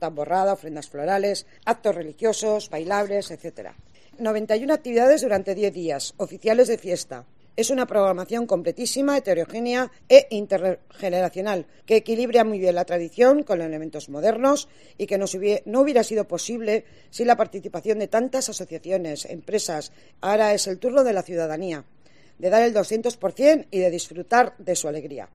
Ana del Val, diputada de Cultura de Álava, avanza el programa festivo de San Prudencio